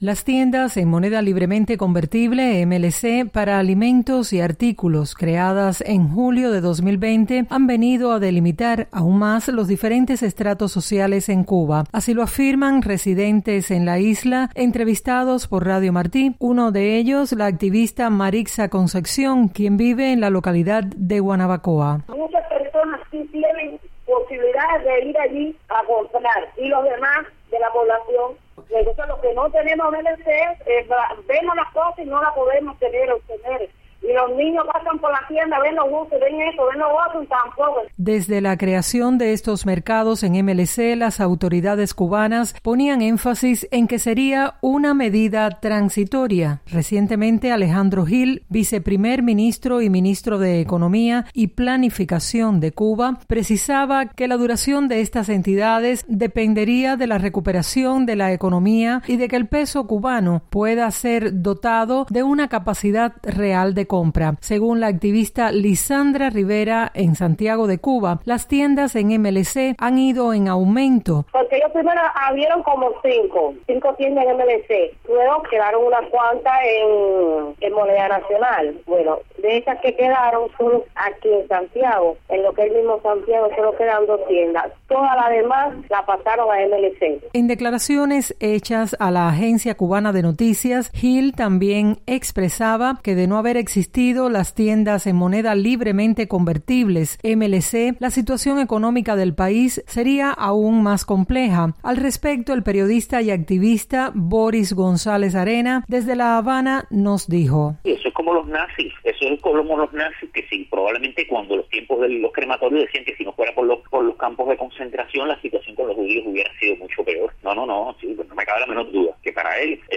El descontento persiste, como afirmaron el viernes a Radio Martí varios cubanos residentes en la isla.